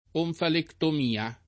onfalectomia [ onfalektom & a ]